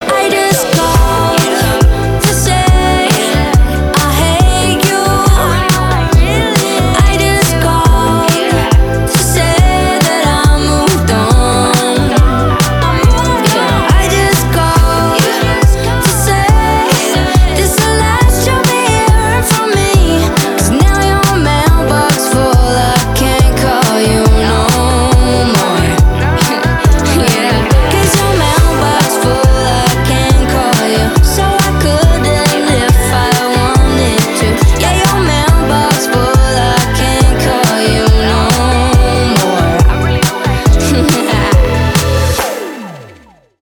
поп
спокойные
красивый женский голос